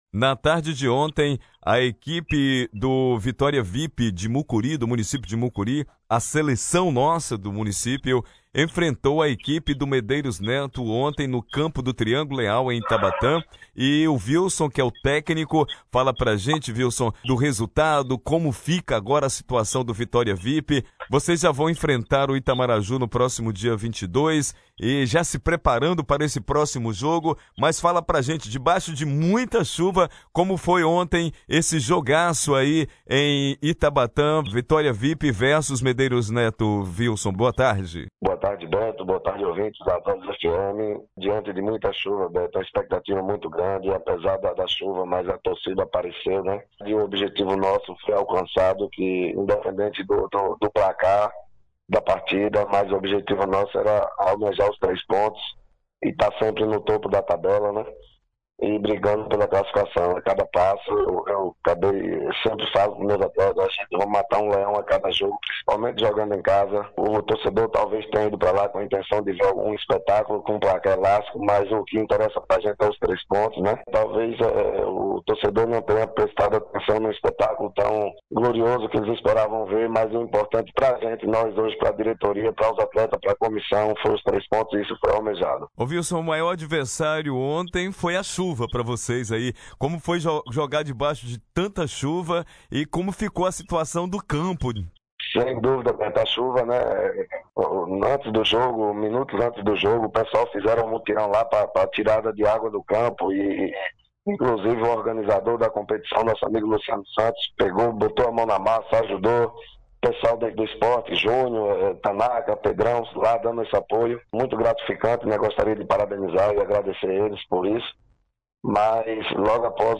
em entrevista ao FM News